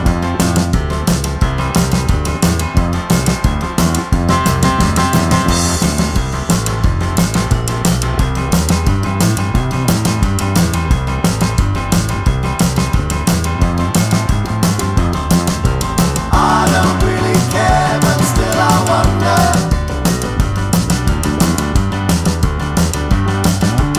no Backing Vocals Rock 'n' Roll 3:19 Buy £1.50